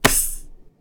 playerHurt.ogg